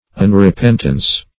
Unrepentance \Un`re*pent"ance\, n.